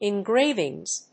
/ɪˈngrevɪŋz(米国英語), ɪˈngreɪvɪŋz(英国英語)/